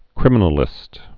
(krĭmə-nə-lĭst)